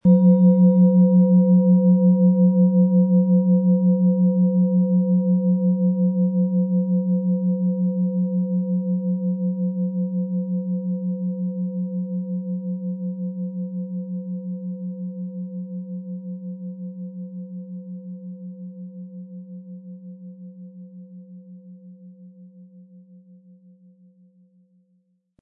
Von Hand hergestellte Klangschale mit dem Planetenton Sonne.
Im Audio-Player - Jetzt reinhören hören Sie genau den Original-Ton der angebotenen Schale.
Spielen Sie die Schale mit dem kostenfrei beigelegten Klöppel sanft an und sie wird wohltuend erklingen.
PlanetentonSonne & Wasser (Höchster Ton)
MaterialBronze